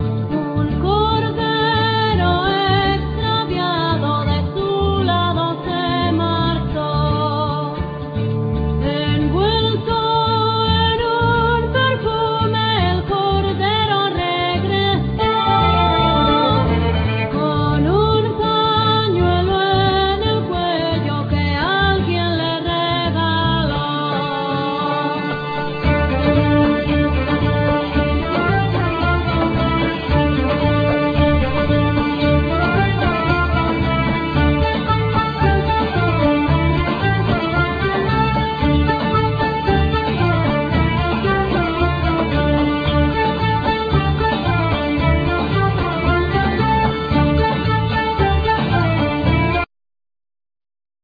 Vocal
Flute,Travesera,Gaita
Violin,Mandolin
El.guitar,Flamenco+Spanish guitar
Ud,Buzuki,Zanfona,Percussions